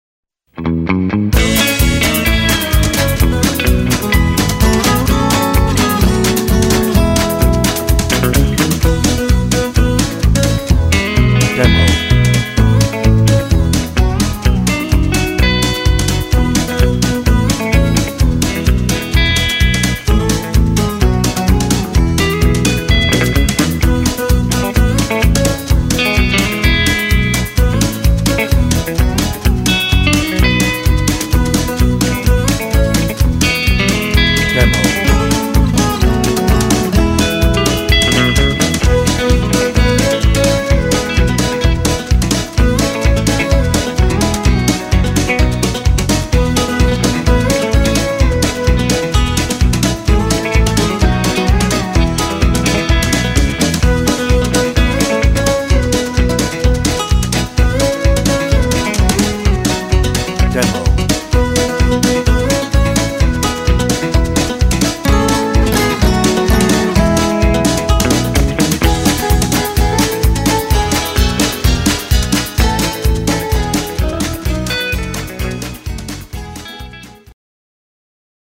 Mainstream
Instrumental